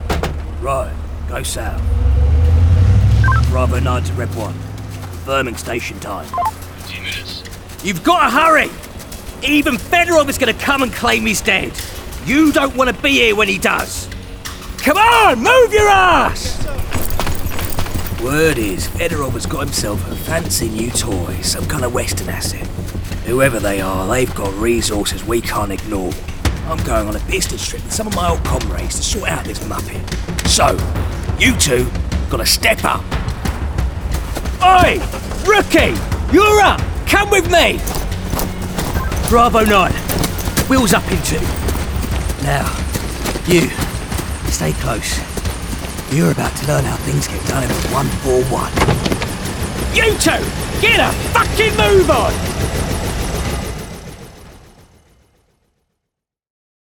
Cockney Accent Showreel
Male
2-cockney-accent-reel.wav